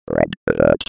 دانلود صدای ربات 11 از ساعد نیوز با لینک مستقیم و کیفیت بالا
جلوه های صوتی